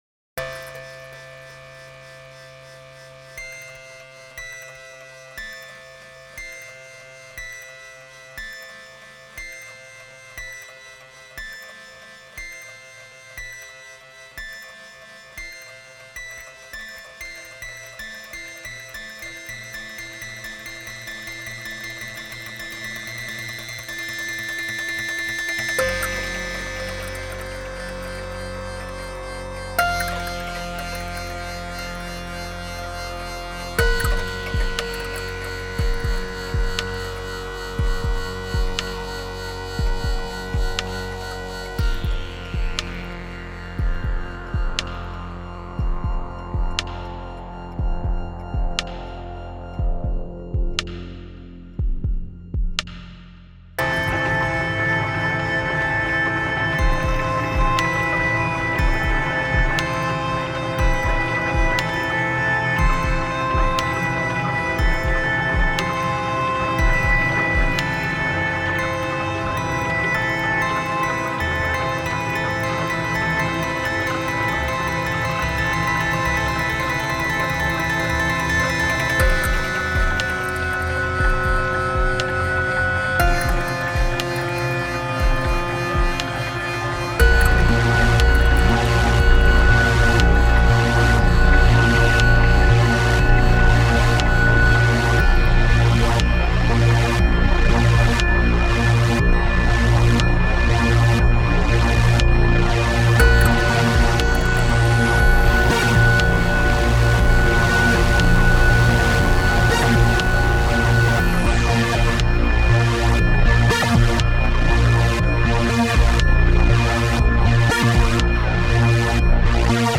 An instrumental piece, 'The Question'.